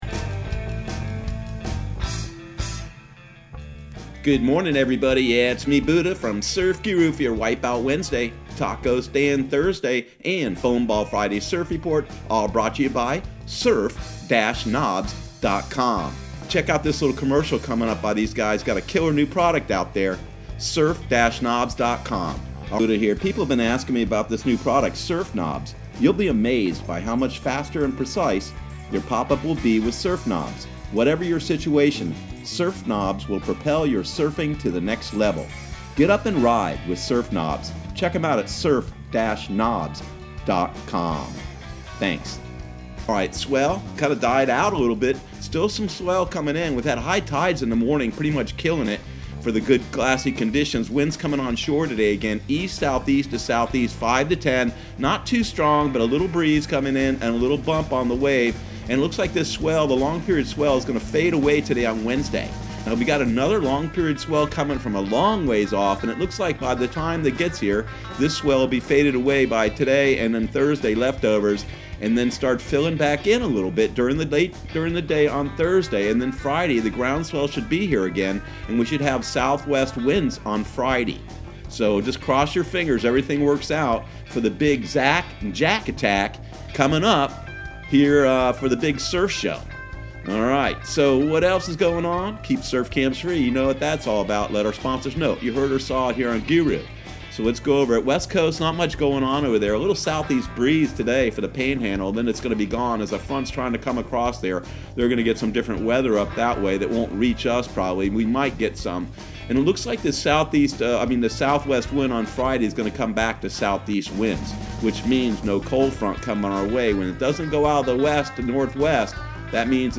Surf Guru Surf Report and Forecast 10/30/2019 Audio surf report and surf forecast on October 30 for Central Florida and the Southeast.